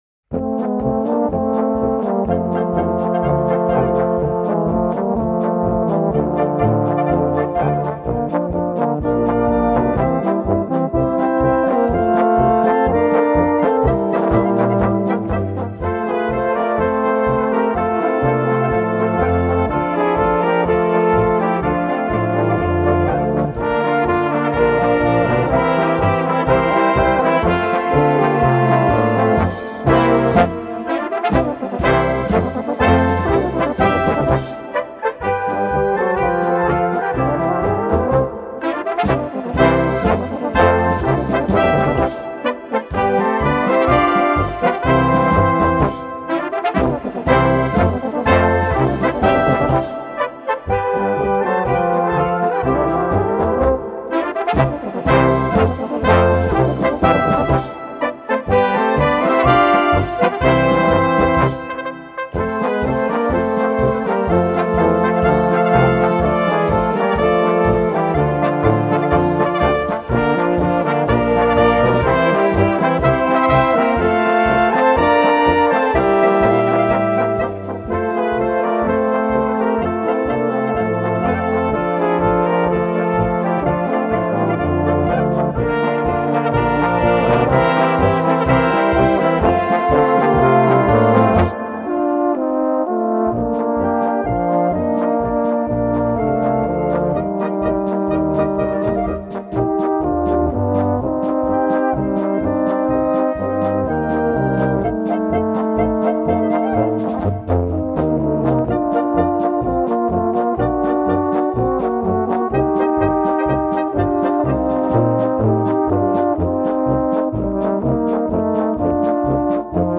Polka für böhmische Besetzung
Kleine Blasmusik-Besetzung